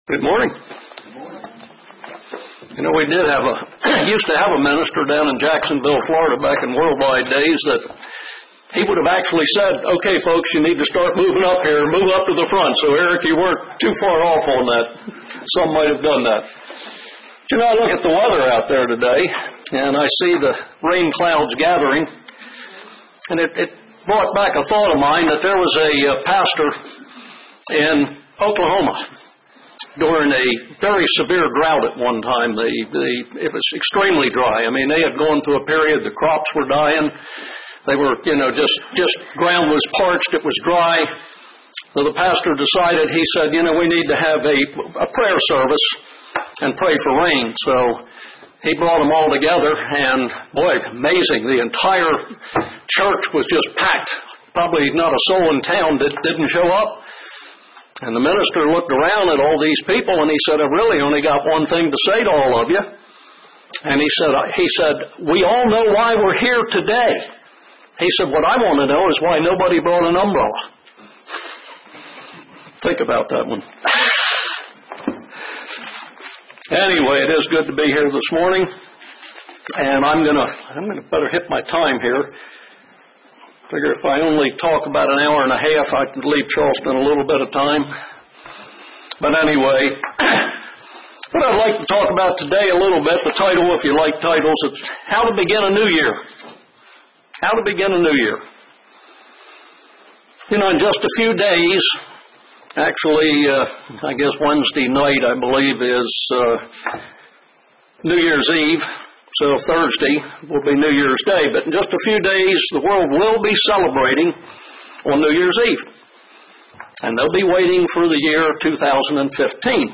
Given in Murfreesboro, TN
UCG Sermon Studying the bible?